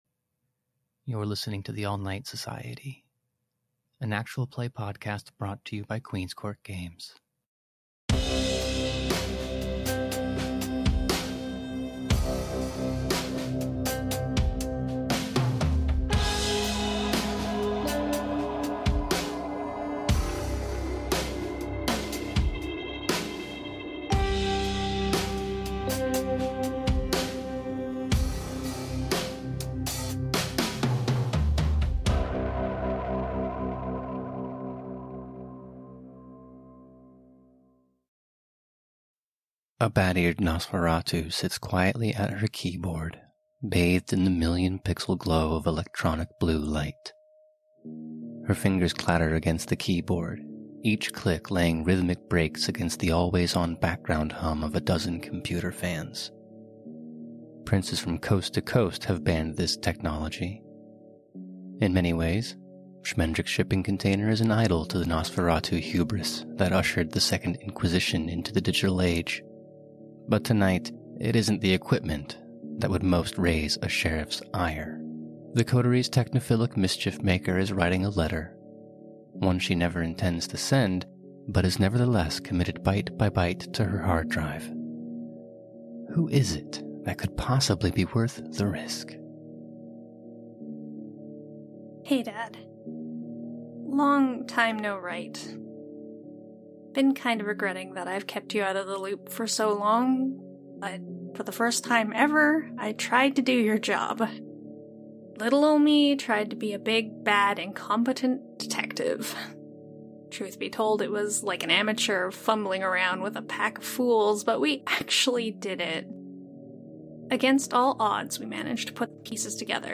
TANS-BONUS-Dear-Charles-w-music.mp3